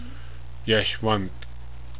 Pronounce